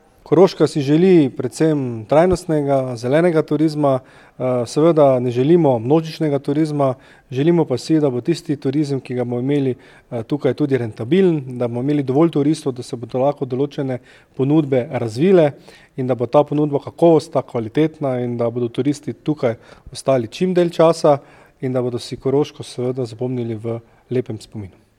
V Mislinji je včeraj potekala okrogla miza o tem, kako se izogniti pastem množičnega turizma na Pohorju.